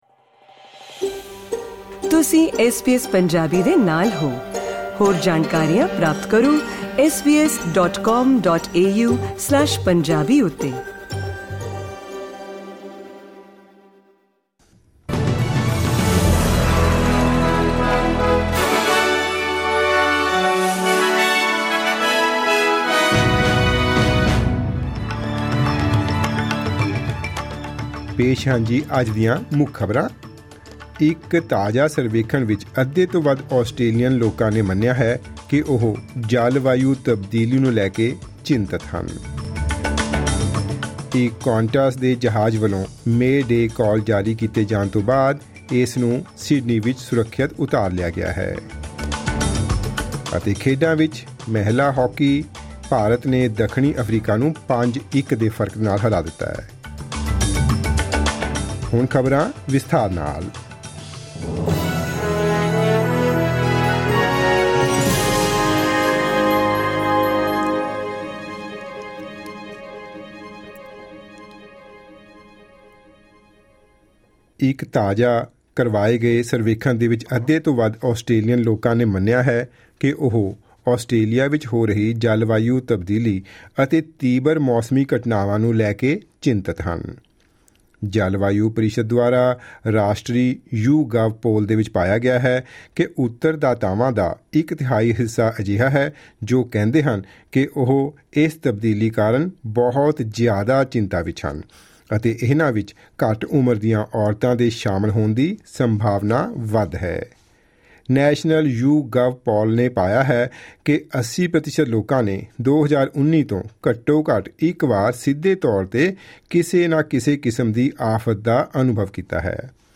SBS Punjabi Australia News: Wednesday 18 January 2023